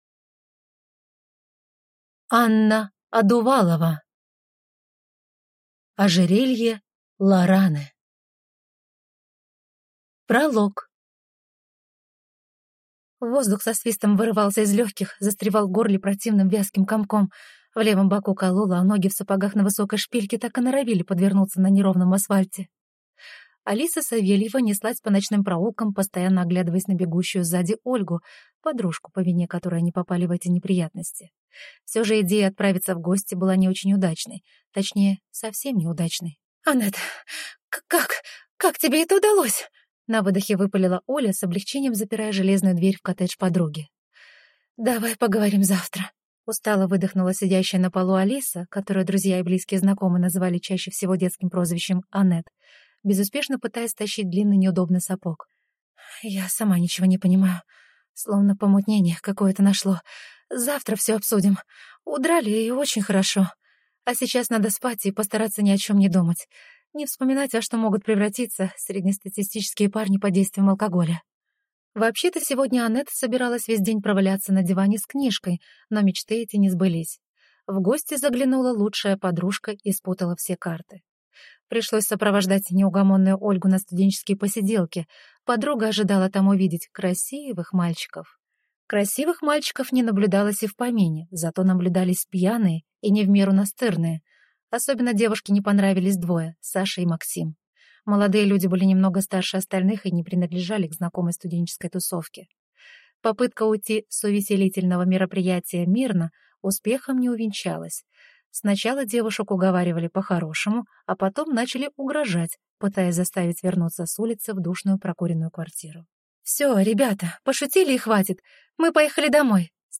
Аудиокнига Ожерелье Лараны | Библиотека аудиокниг